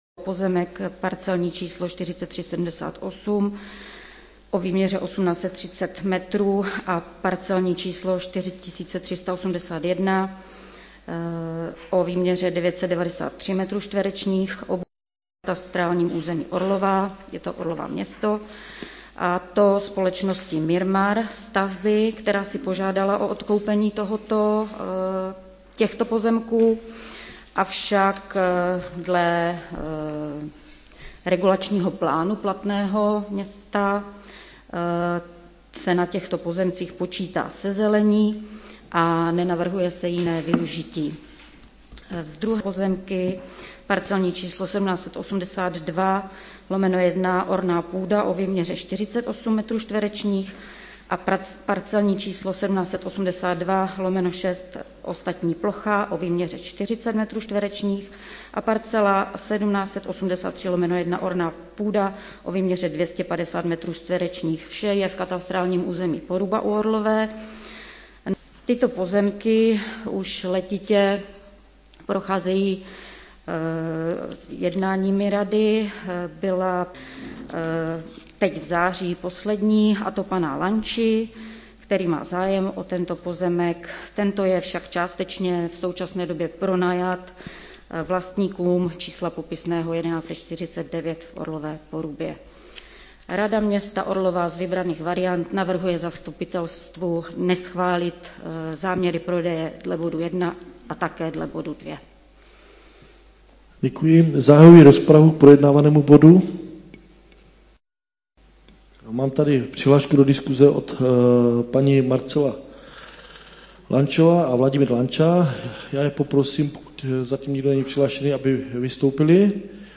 Město Orlová: XV.. zasedání Zastupitelstva města Orlové ze dne 7.12.2020 XV. zasedání Zastupitelstva města Orlové – živé vysílání ff0708f787524ffd74a8f3b46c3d1bff audio